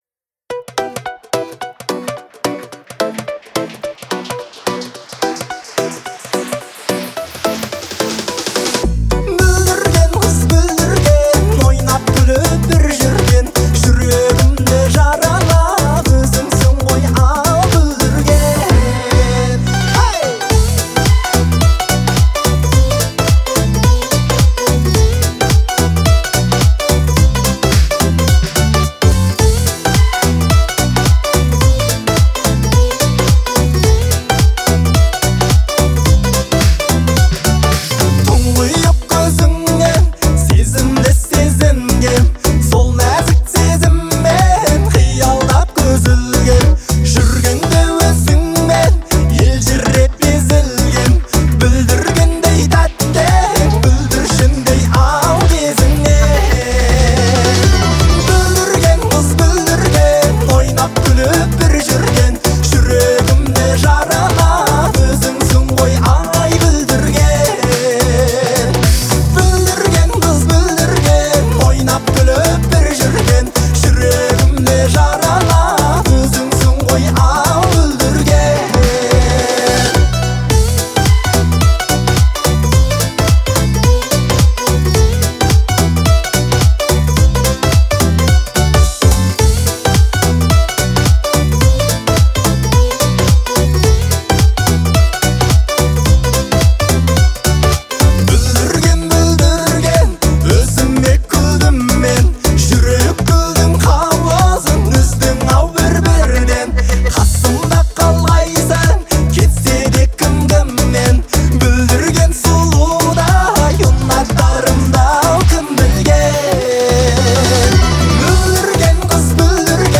это яркая и мелодичная песня в стиле казахского поп-фолка